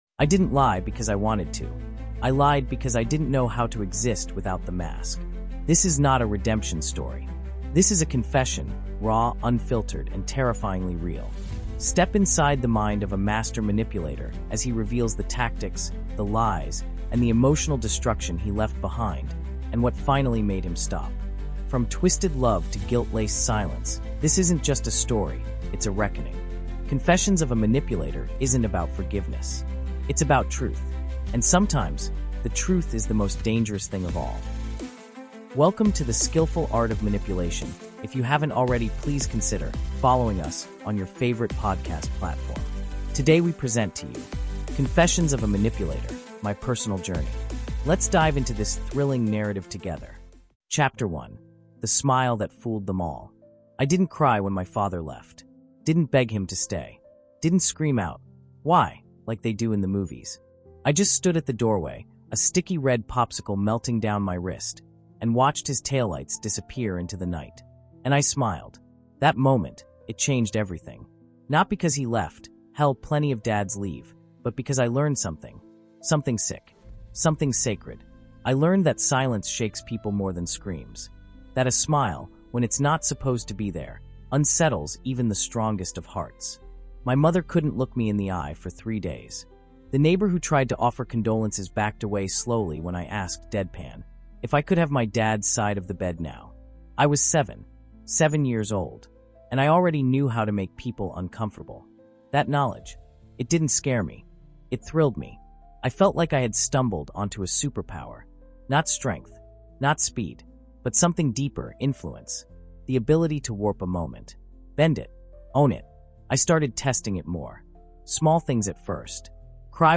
Confessions Of A Manipulator: My Personal Journey | Audiobook
Confessions of a Manipulator: My Personal Journey is a chilling, intimate, and emotionally raw 7-chapter audiobook that takes listeners deep into the mind of a master manipulator—told in his own words. What begins as quiet control in childhood spirals into emotional warfare in adulthood, leaving a trail of broken trust, warped love, and quiet devastation.
Told in a first-person voice with searing honesty, Confessions of a Manipulator delivers an immersive, emotionally driven experience built for listeners who crave truth, pain, and the possibility of change.